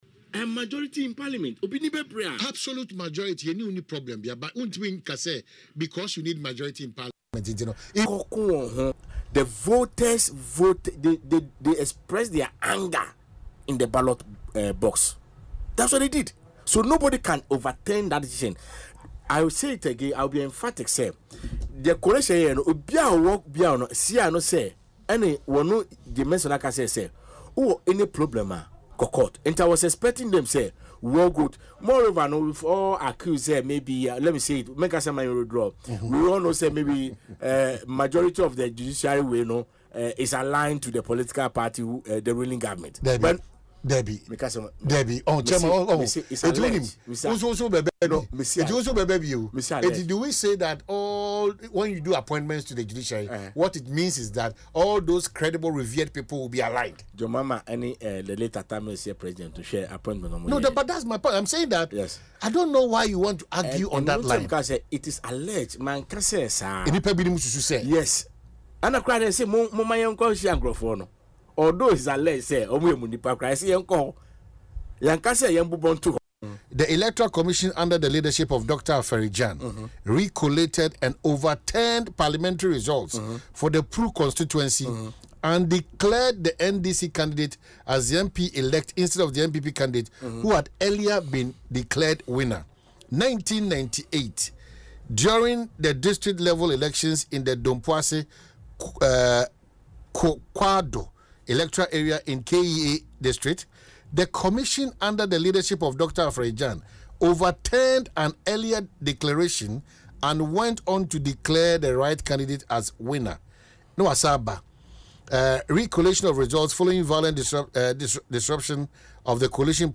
In an interview on Asempa FM’s Ekosii Sen show